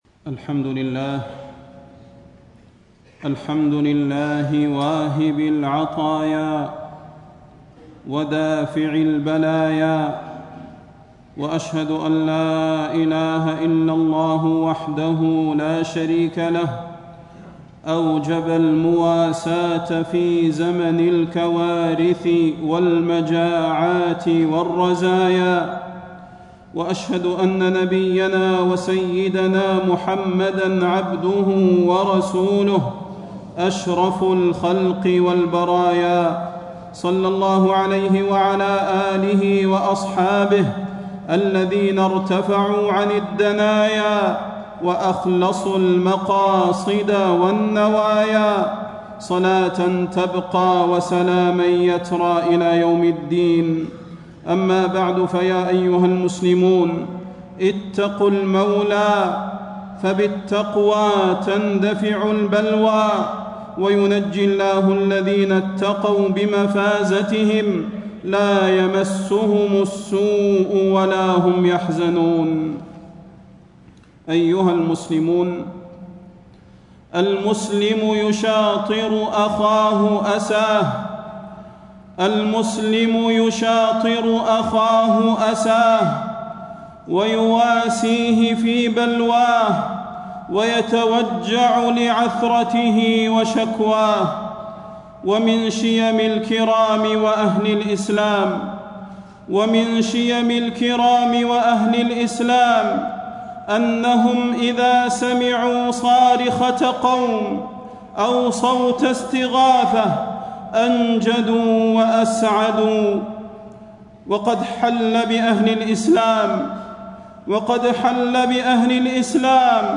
تاريخ النشر ٢٤ صفر ١٤٣٥ هـ المكان: المسجد النبوي الشيخ: فضيلة الشيخ د. صلاح بن محمد البدير فضيلة الشيخ د. صلاح بن محمد البدير حق المسلم على أخيه المسلم